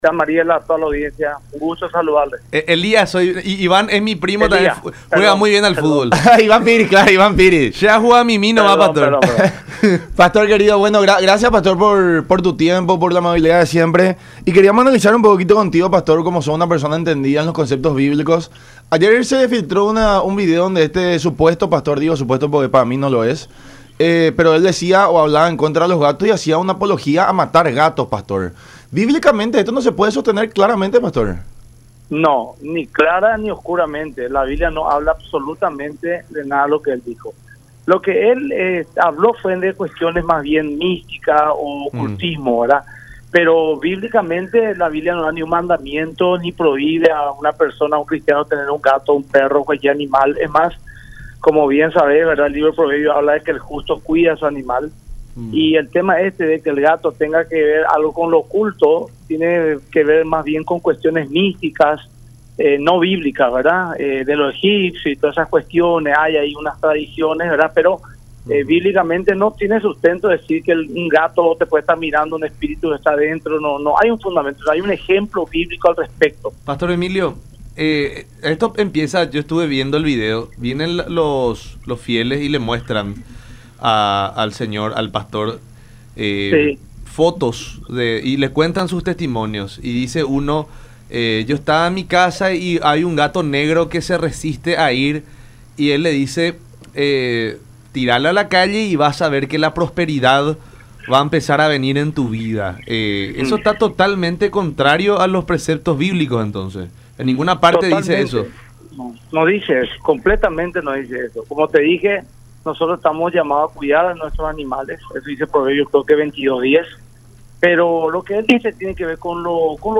en diálogo con La Unión Hace La Fuerza por Unión TV y radio La Unión